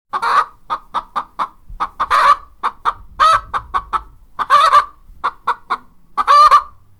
Sound Effects
Chicken Clucking Type 3